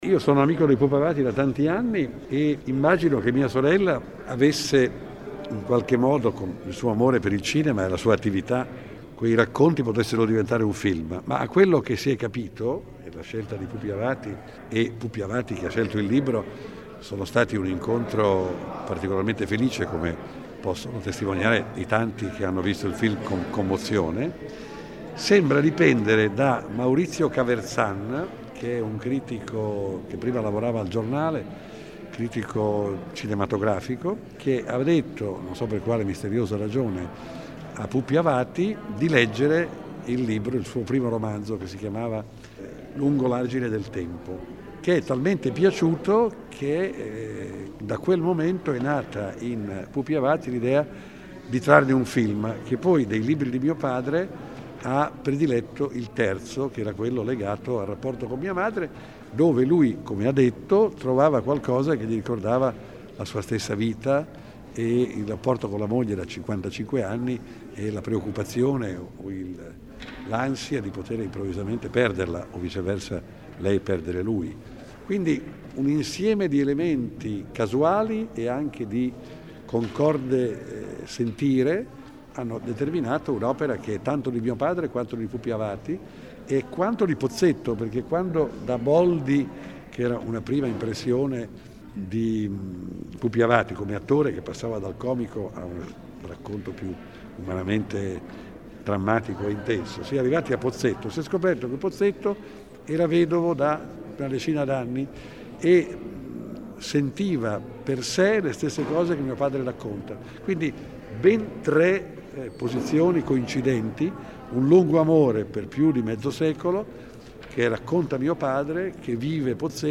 Ascolta l’intervista a Vittorio Sgarbi